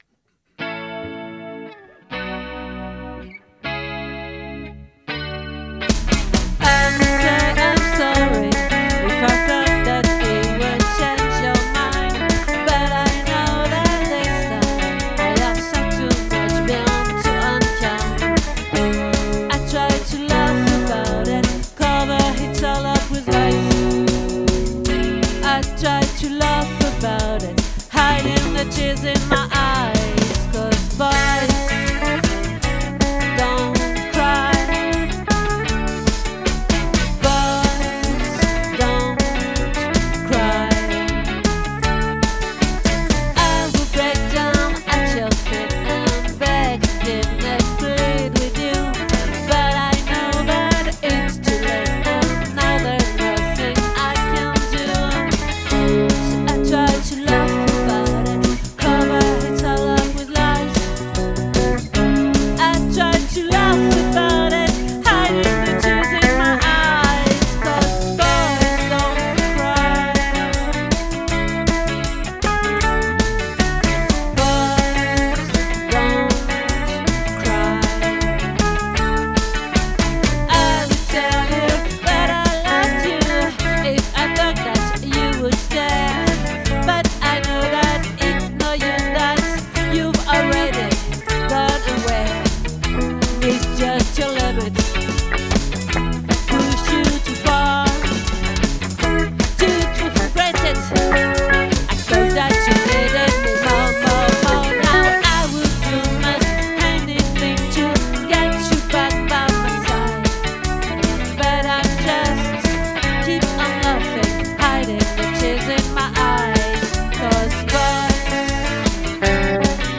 🏠 Accueil Repetitions Records_2025_01_13